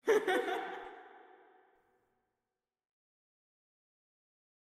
MB SO ICEY 2 GL CHANT (1).wav